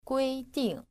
• guīdìng